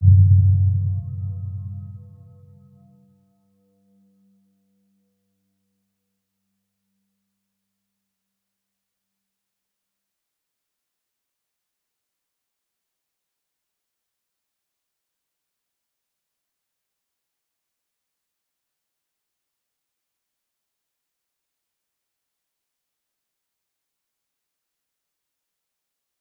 Round-Bell-G2-mf.wav